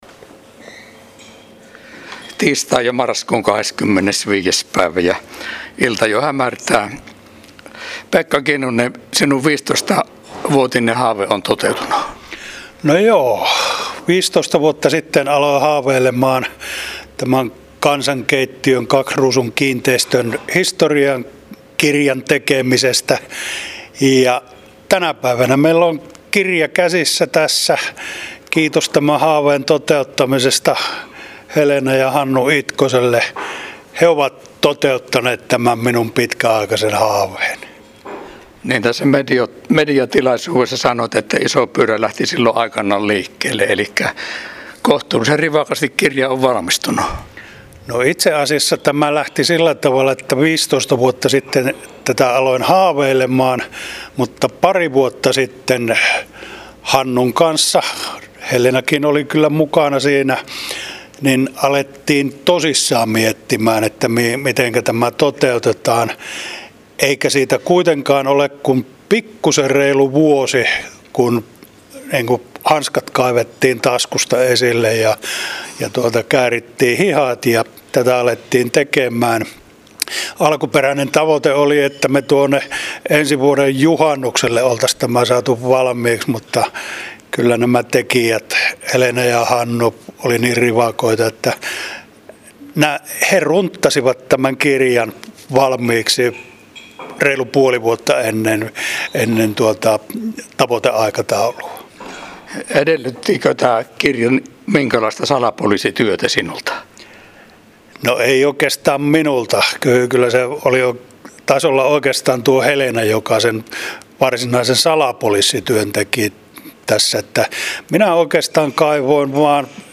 Teos julkistettiin tiistaina 25.11. Ravintola Kaks Ruusussa Varkaudessa.